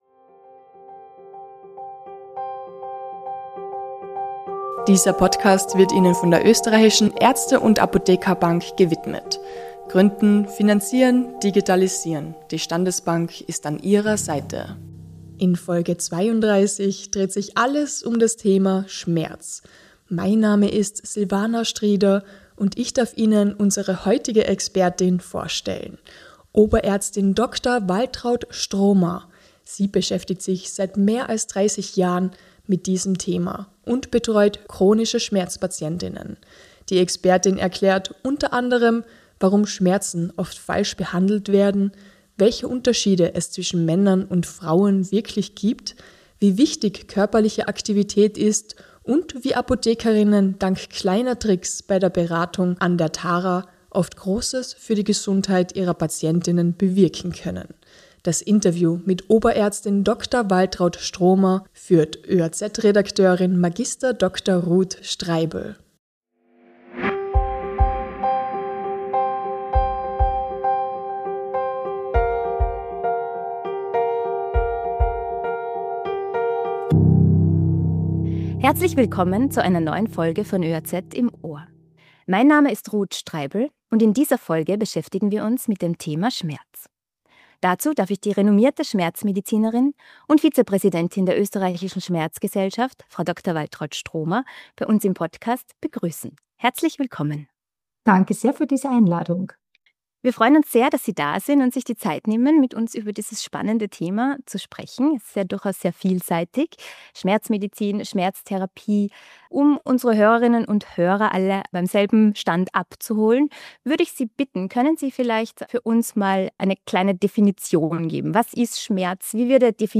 Die Expertin erklärt u.a., warum Schmerzen oft falsch behandelt werden, welche Unterschiede es zwischen Männern und Frauen wirklich gibt, wie wichtig körperliche Aktivität ist und wie Apotheker:innen dank kleiner Tricks bei der Beratung an der Tara, oft großes für die Gesundheit ihrer Patient:innen bewirken können. Das Interview